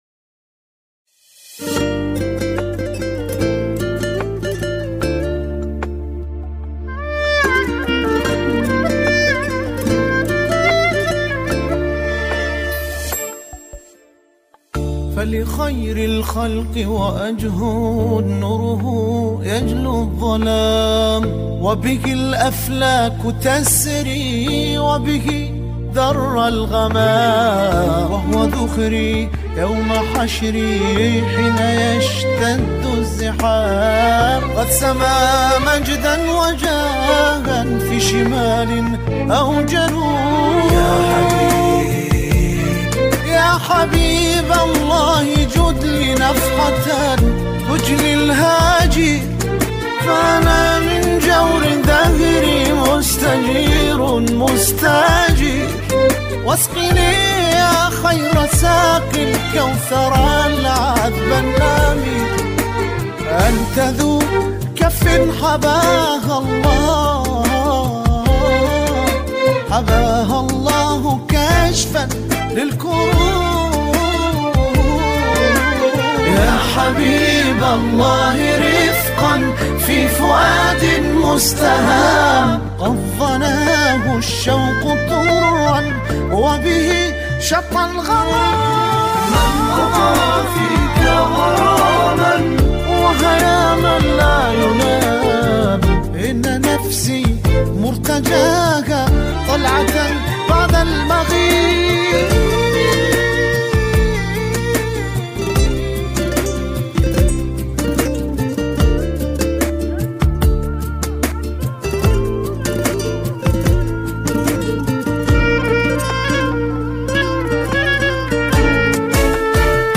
نماهنگ سرود